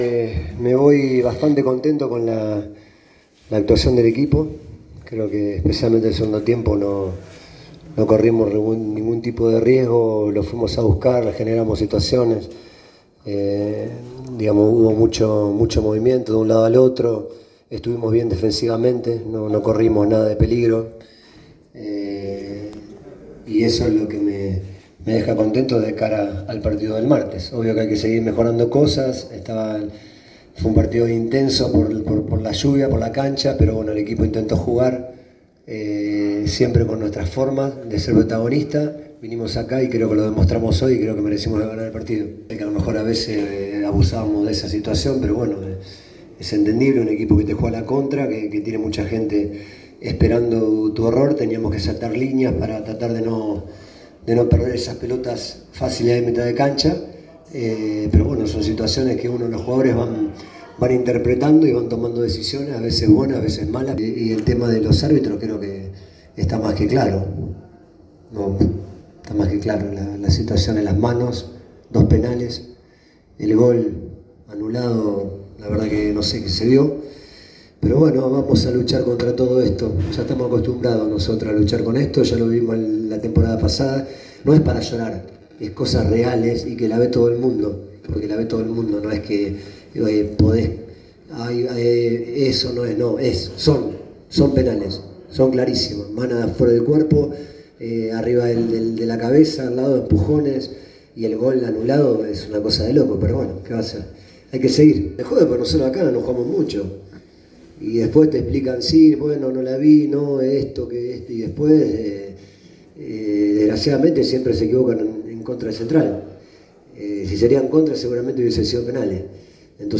“Me voy bastante contento con la actuación del equipo. Creo que especialmente en el segundo tiempo no corrimos riesgos y lo fuimos a buscar. Generamos situaciones, nos movimos de un lado al otro y estuvimos bien defensivamente”, señaló el técnico del visitante en la conferencia de prensa.